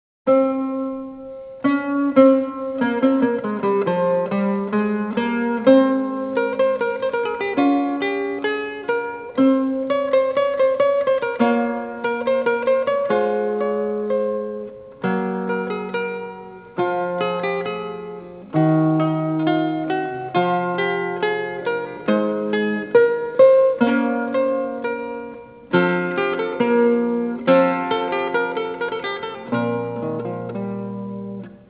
dc.format.medium1 CDhu